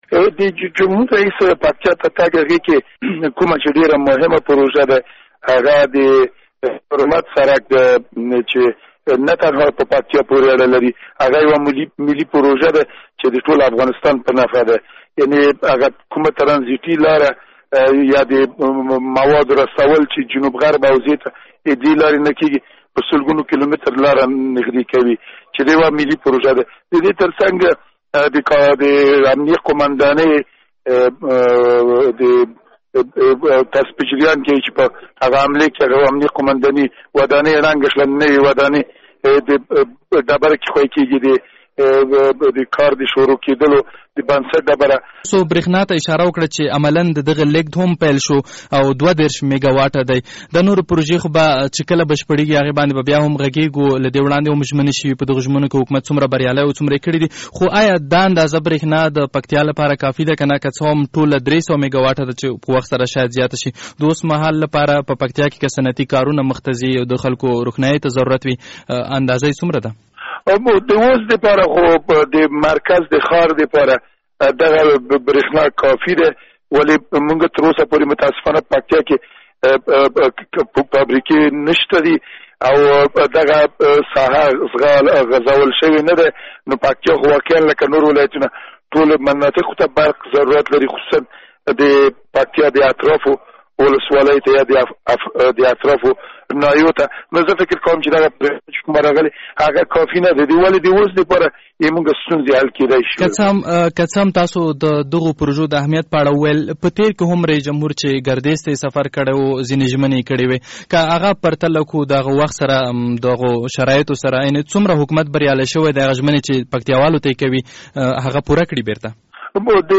له ګل پاچا مجیدي سره مرکه